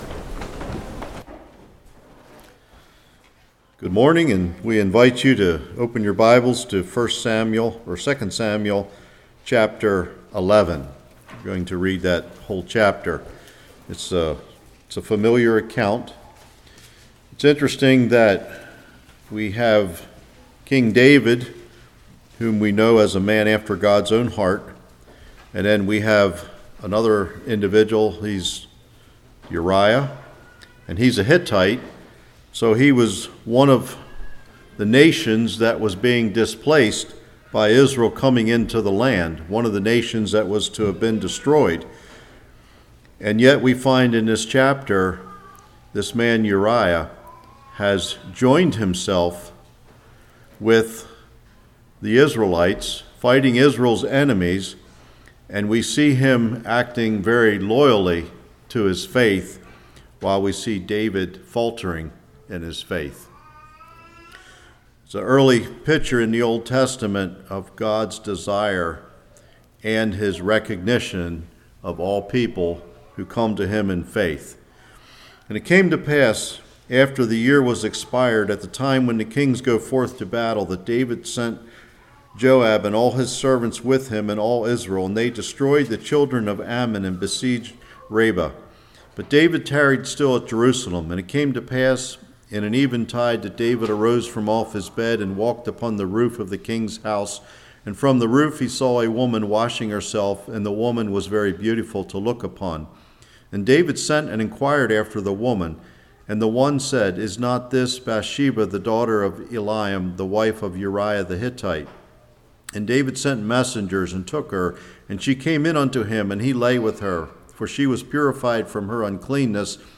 2 Samuel 11:1-27 Service Type: Revival What puts us in a position of vulnerability?